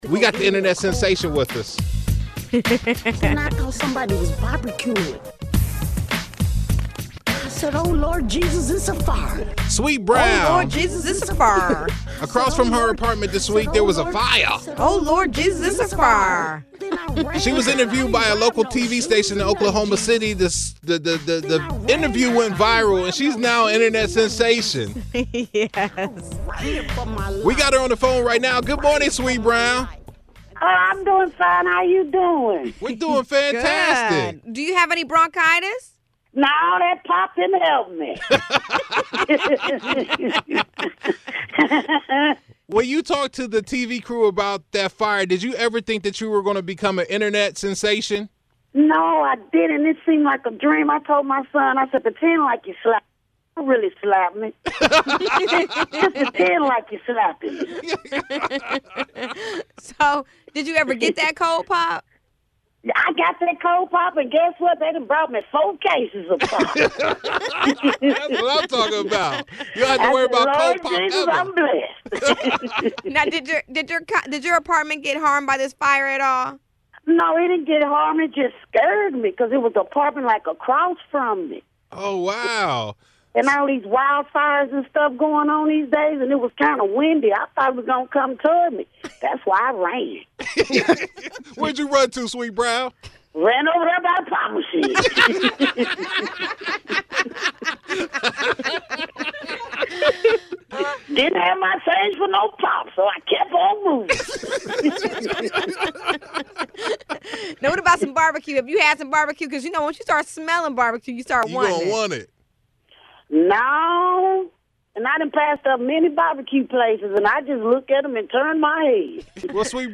The Power Morning Crew recently caught up with Sweet Brown and we got the update on her bronchitis, getting slapped, barbecue’s, and her ice cold POP. You gotta hear this interview!